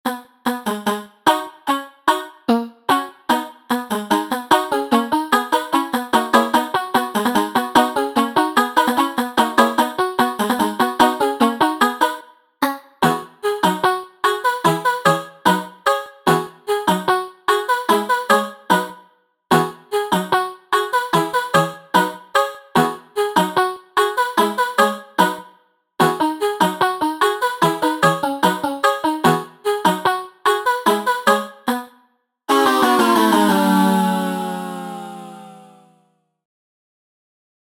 ネタっぽい楽曲なので尺は短めで、よく考えてみたら間違っていたよという風な落胆の「あ」でオチをつけている。
タグ: コミカル 変わり種 明るい/楽しい 爽やか コメント: 何かを閃いた時に「あ」と言うシーンをイメージした楽曲。